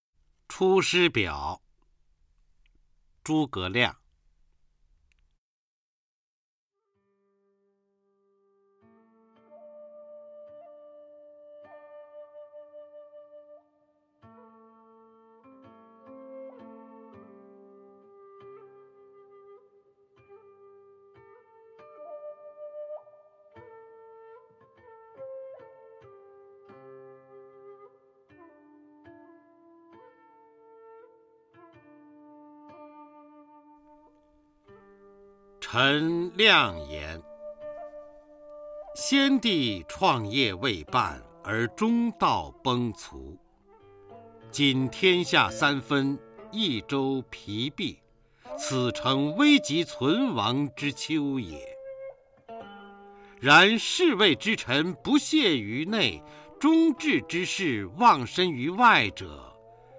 初中生必背古诗文标准朗诵（修订版）（1）-06-方明-出师表 东汉 诸葛亮